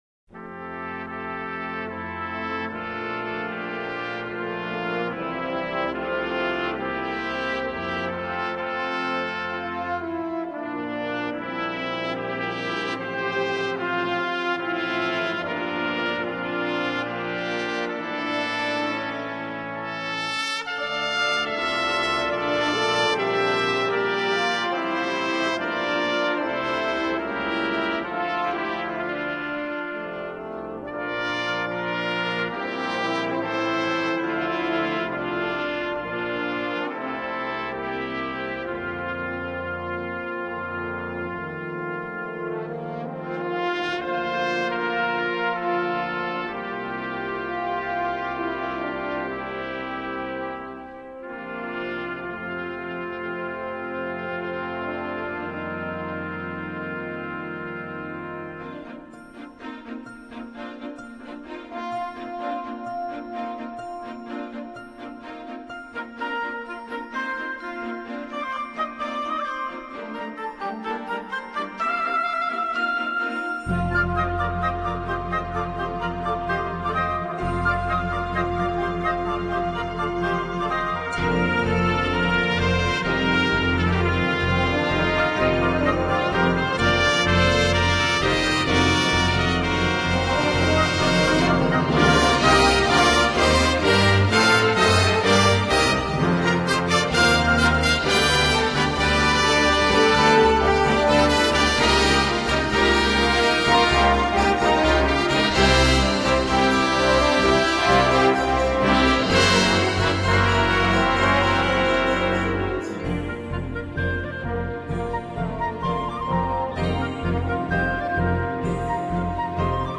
Voicing: Orch,Cond